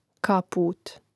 kàpūt kaput